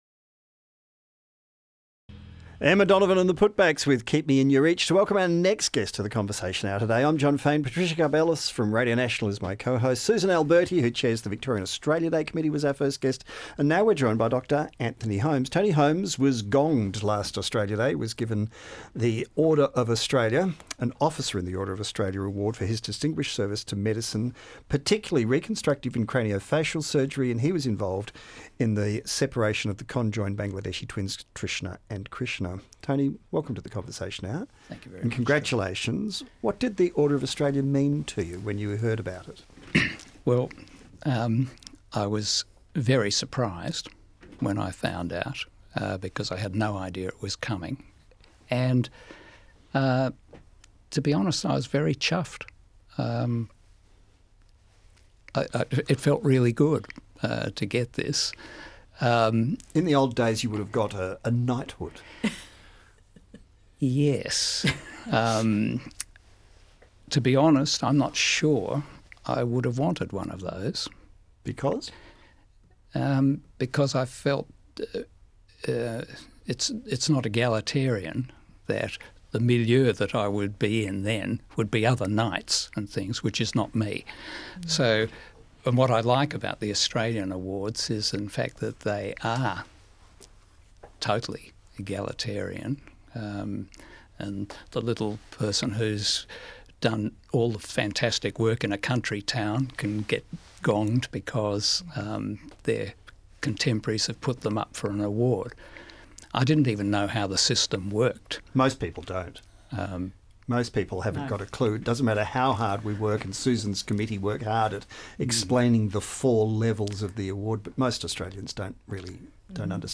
interviewed by Jon Faine on ABC Radio